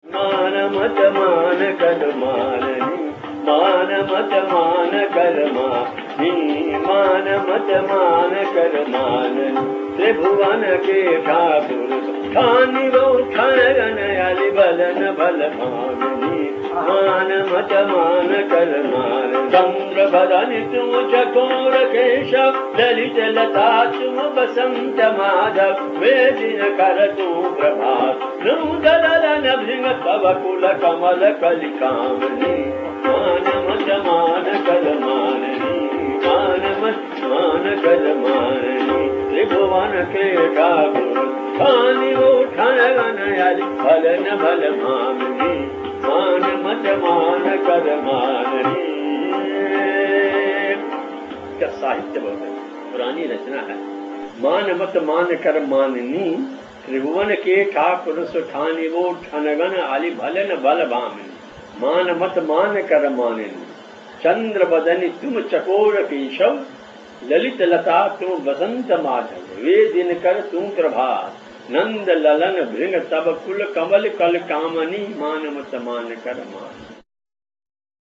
Raga Kamod